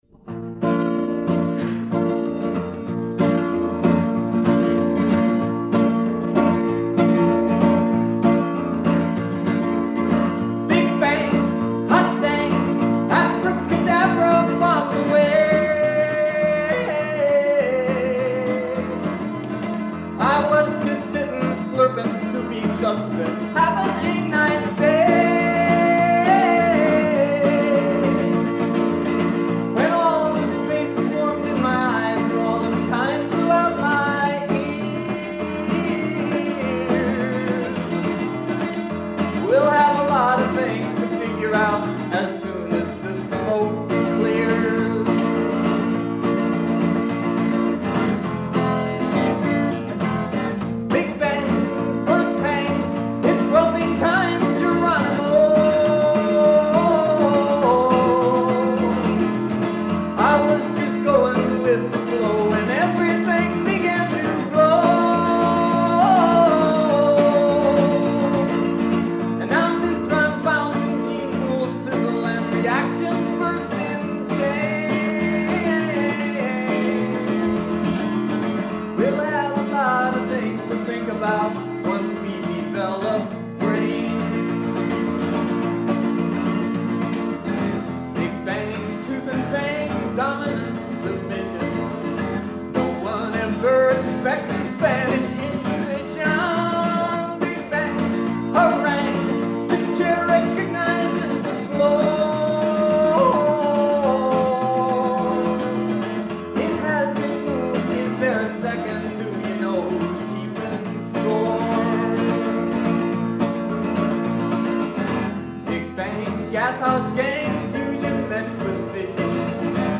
harmony vocals and guitar